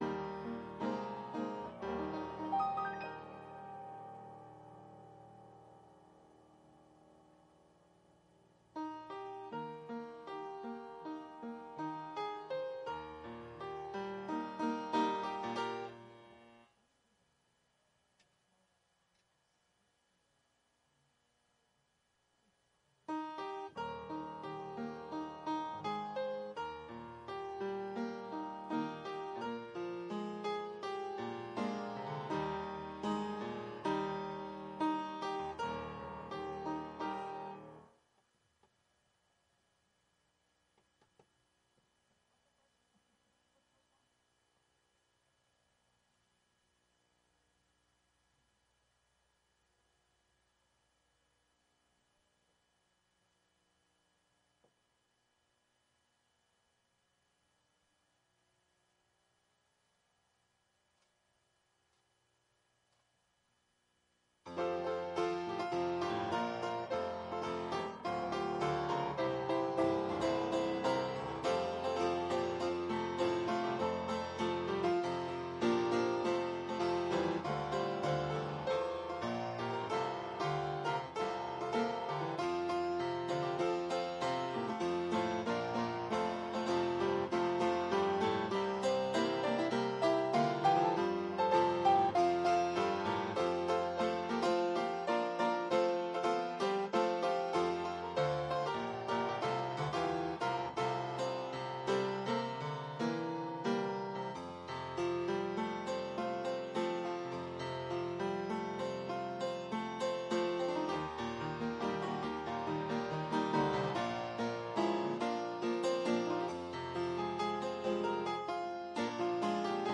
Mid Week Bible Study From Esther